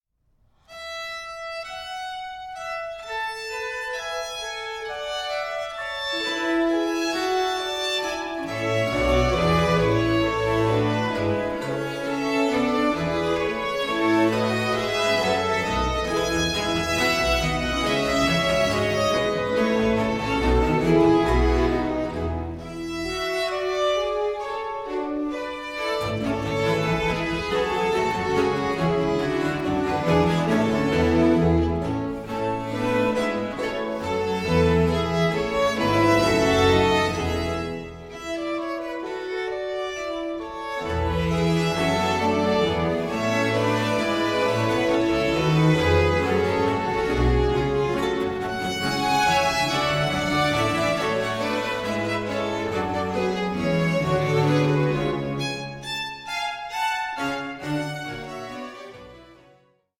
Adagio 1:35